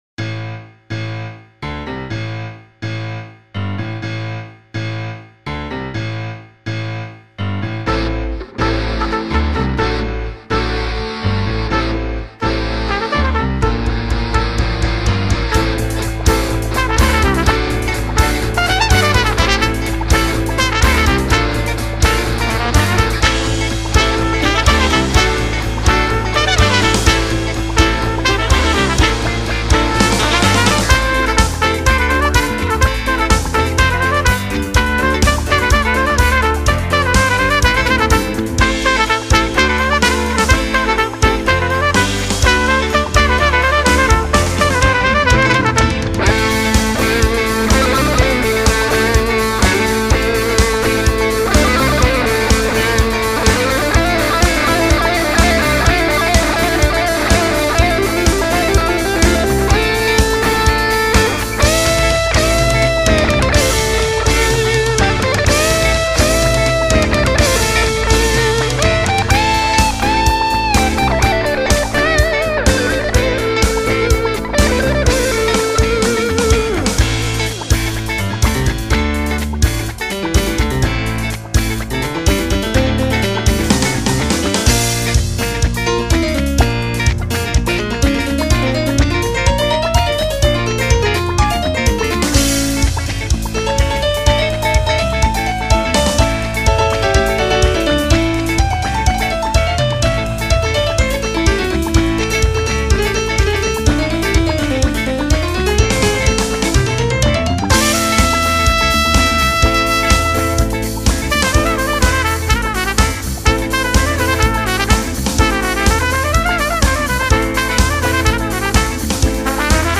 Ethno folk; folk rock; jazz; mashup; contemporary
(mainly) instrumental mashup